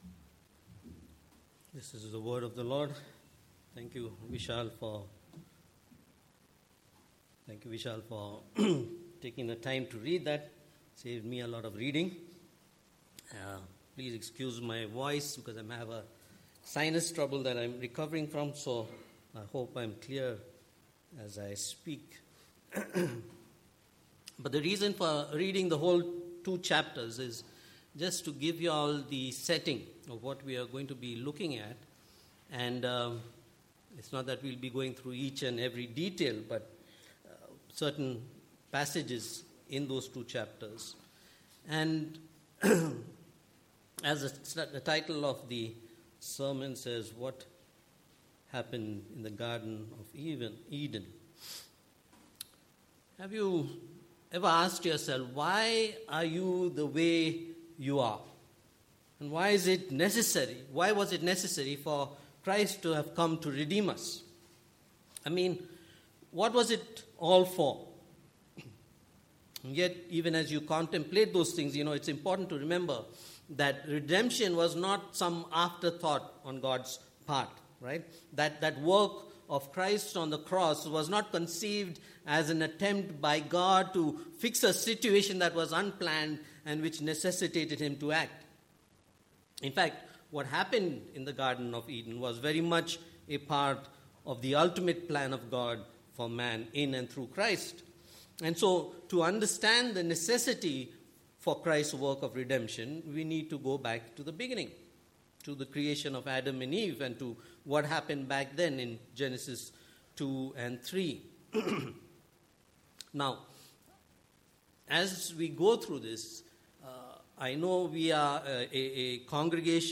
Passage: Genesis 2 & 3 Service Type: Sunday Morning « The Reason